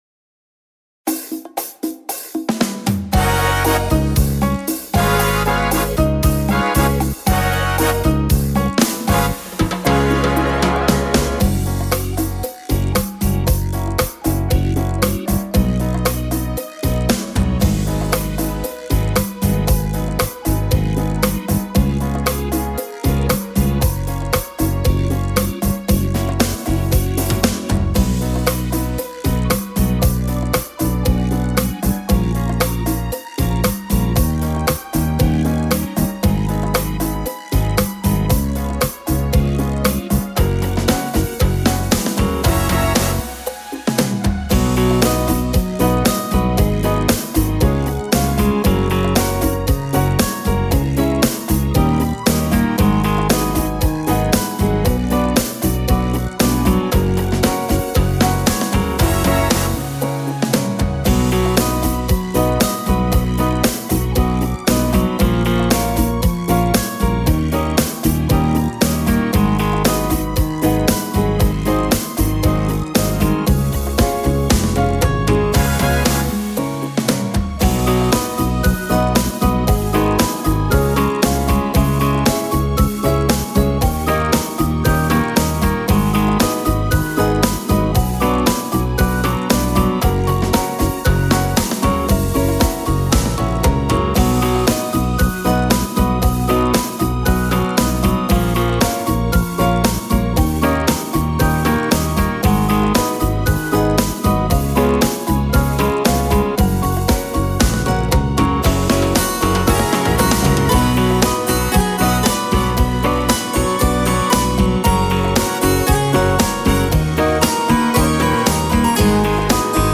Tone Nam (F#) - Nữ (C)